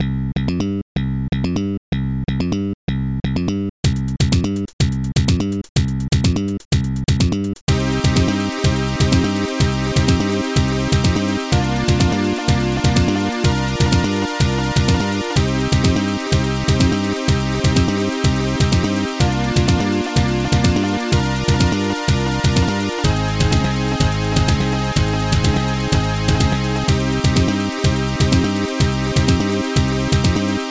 Scream Tracker Module  |  1996-03-11  |  35KB  |  2 channels  |  44,100 sample rate  |  30 seconds
Slap Bass
hihatcl-boss
ZlamBaseDrum.HH
Synth Strings (Chord Minor)
Synth Strings (Chord Major
screen sound to it though.
This song is meant to loop!